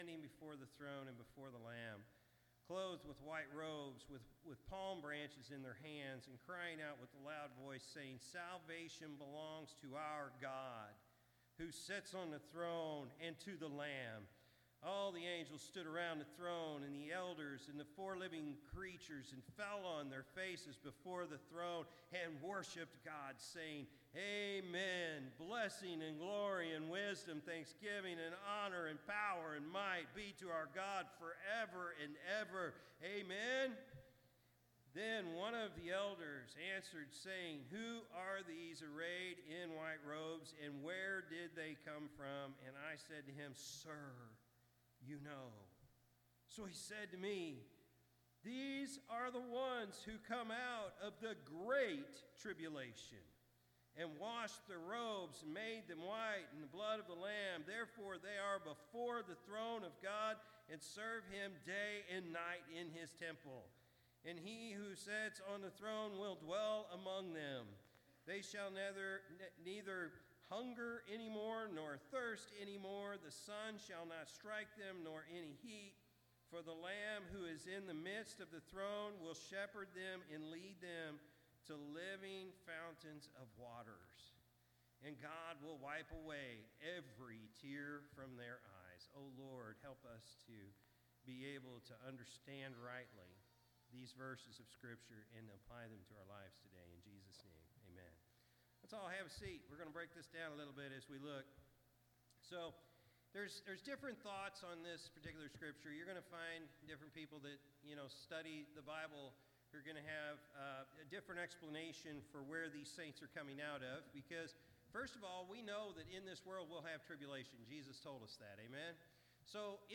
September-8-2024-Morning-Service.mp3